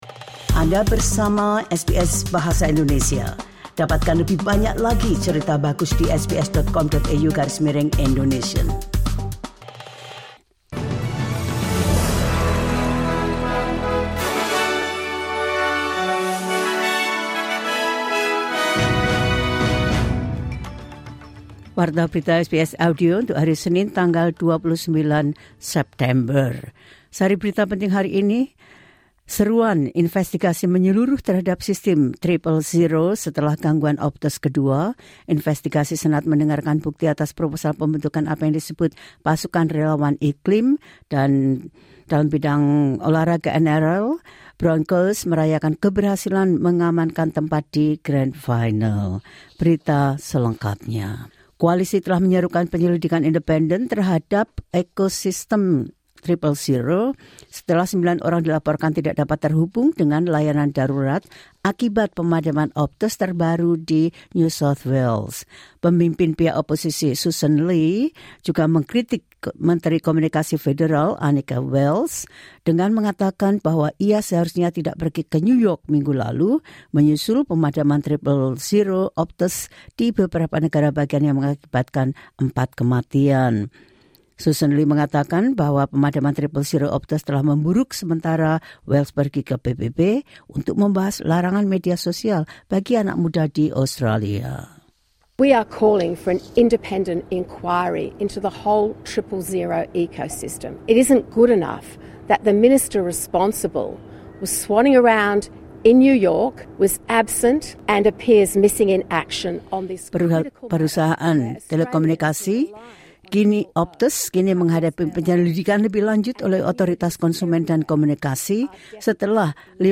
Latest News SBS Audio Indonesian Program – 29 September 2025